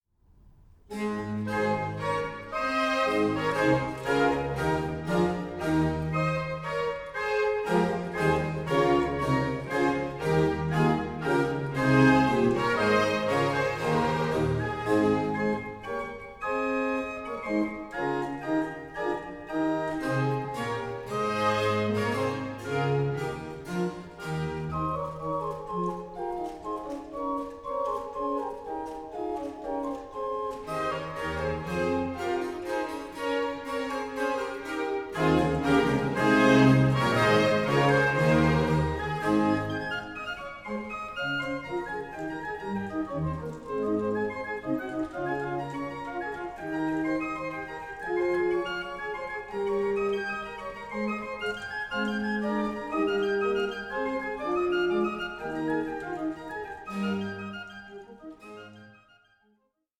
Organ Concerto No.14 in A major